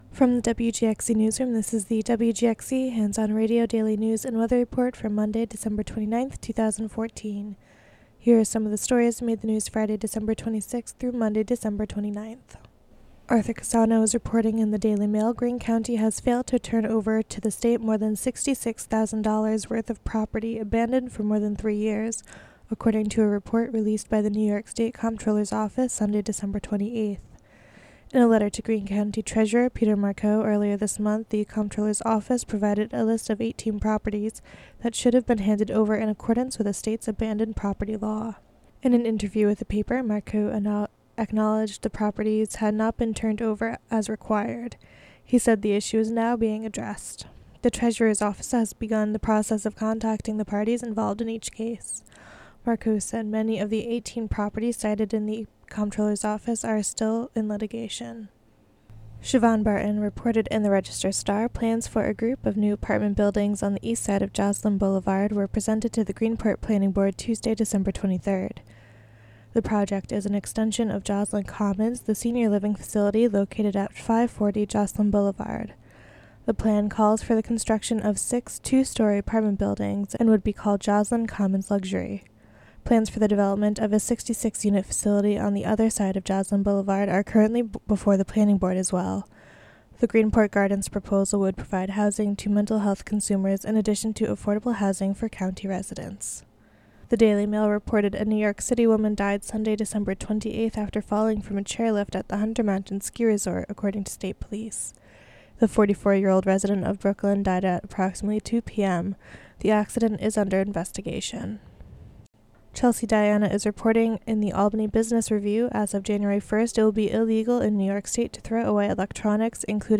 (4:15) Local news and weather for Monday, December 29, 2014.